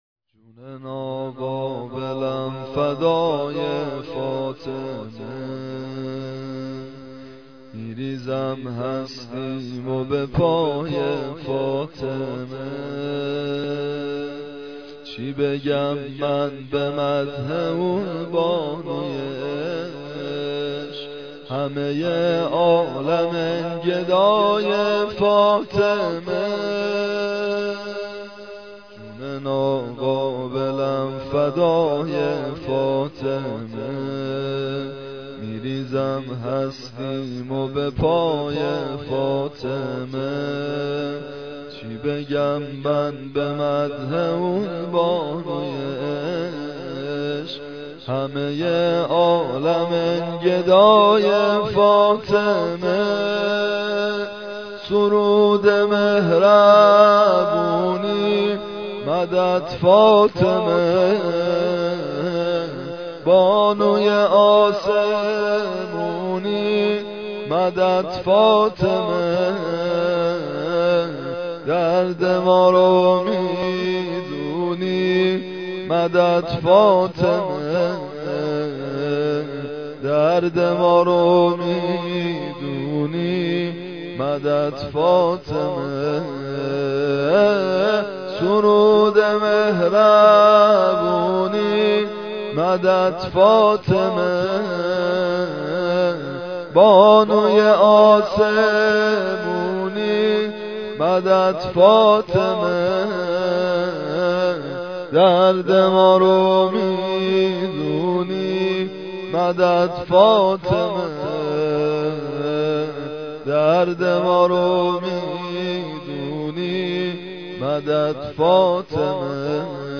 نوحه زمينه براي مناجات حضرت زهرا (س) ( جون ناقابلم فدای فاطمه)